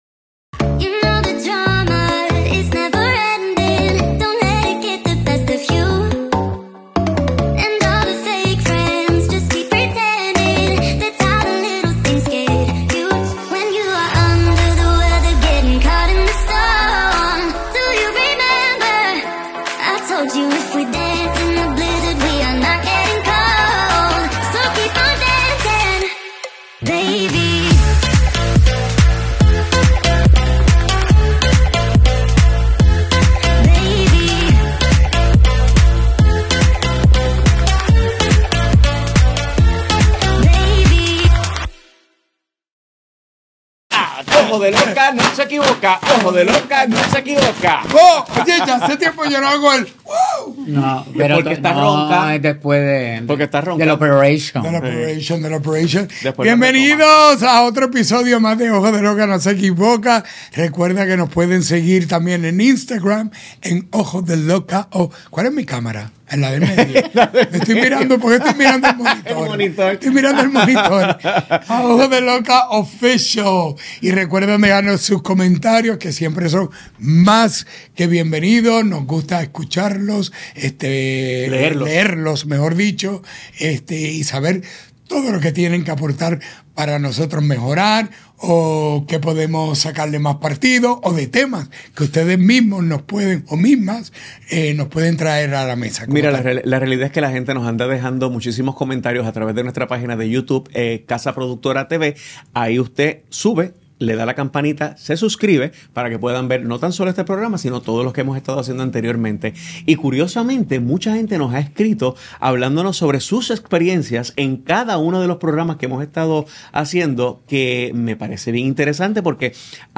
Antes de terminar el año, no podiamos dejar de hablar sobre un tema que nos habian pedido… las Bodas y Actividades especialmente para nuestra cominidad. Y quisimos traer a una bella y experta mujer a que nos conversara y se uniera a este relajo instructivo…